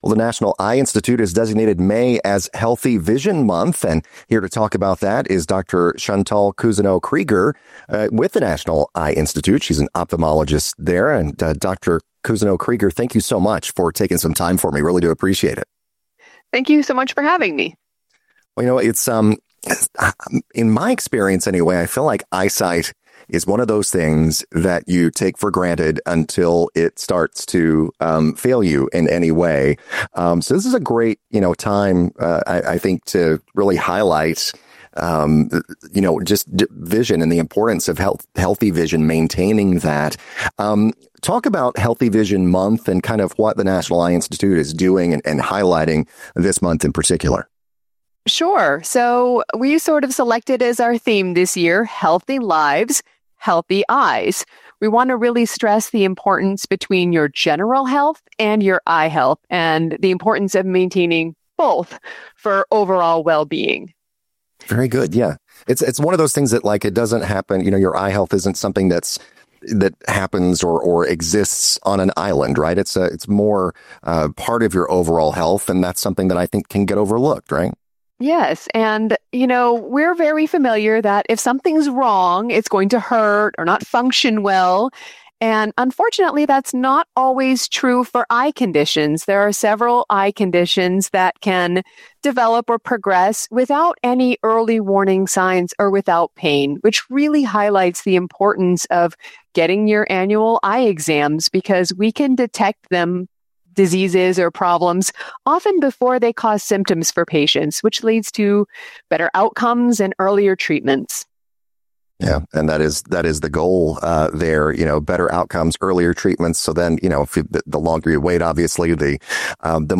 Bonus Interview: Healthy Eyes, Healthy Lives: Vision Tips from the National Eye Institute